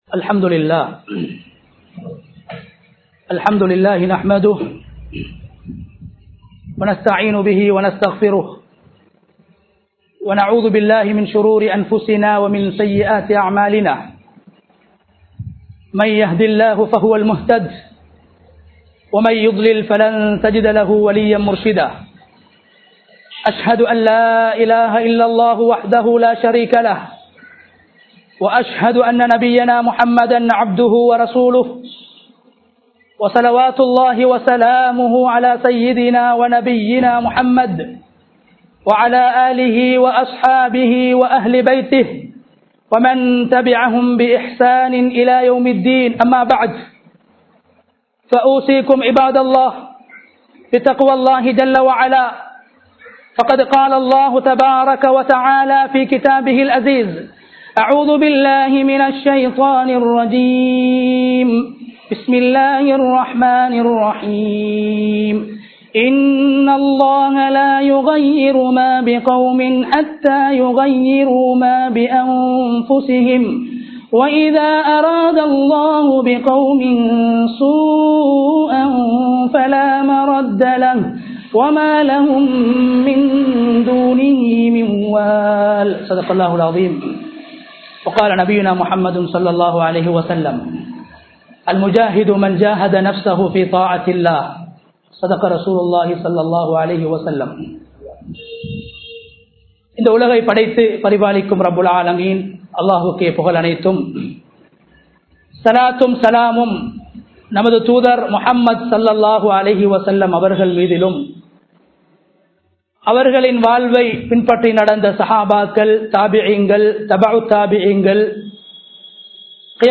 நெருக்கடியும் நோன்பும் | Audio Bayans | All Ceylon Muslim Youth Community | Addalaichenai
Dehiwela, Kawdana Road Jumua Masjidh 2022-04-01 Tamil Download